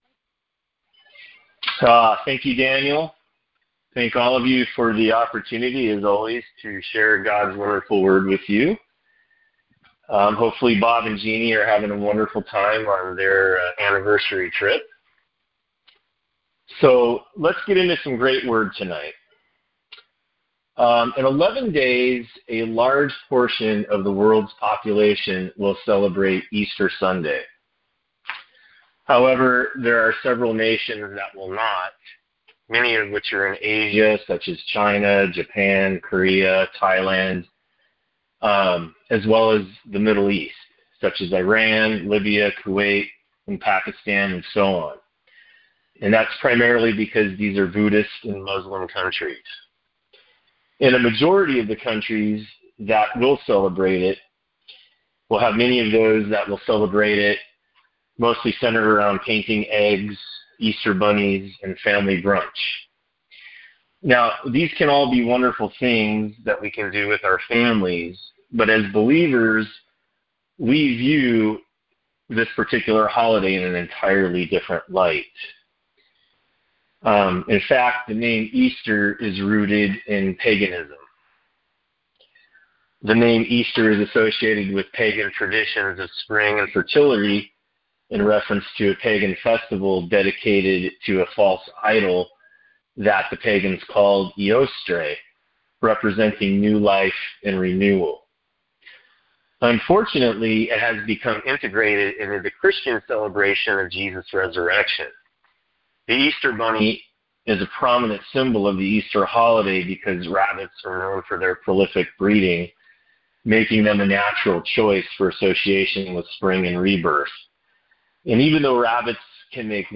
Details Series: Conference Call Fellowship Date: Wednesday, 09 April 2025 Hits: 261 Play the sermon Download Audio ( 13.74 MB ) Acts 28 The End and the Beginning: Acts Lives On!